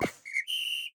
TennisBlockStart.wav